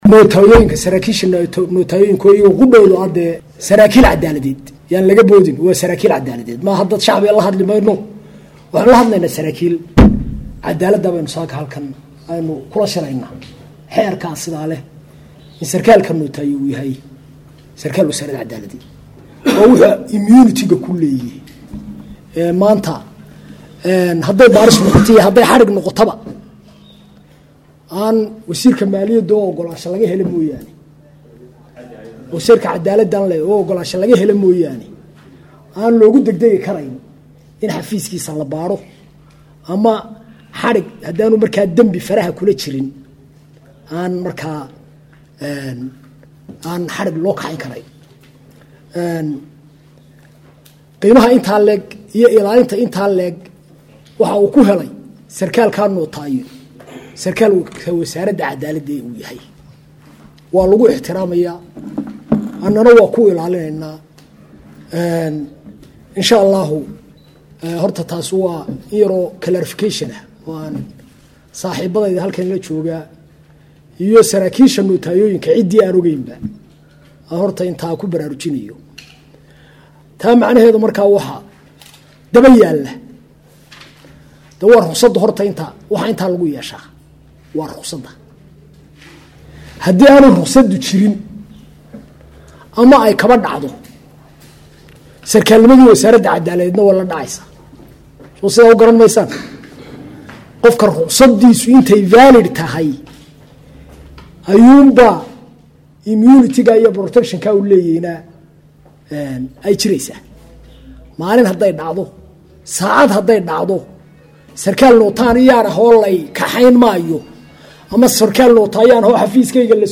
Hargaysa 31.March 2014 (SDN)- Shir lagaga hadlayey kobcinta cashuuraha oo ay soo qabanqaabisay Wasaaradda maaliyadda ayna ka qayb gelayaan qaar ka tisan Wasaaradaha dawladda iyo nootaayooyinka shrciga ah ayaa maanta lagu qabtay xarunta shaqaalaha dawladda ee Magaalada hargaysa.
Wasiirka Wasaarada Cadaalada Somaliland Mudane Xuseen Axmed Caydiid ayaa ka Waramay Siyaabaha lagu waayi karaan Nootaatooyinku Ruqsada Wasaarada Cadaalada.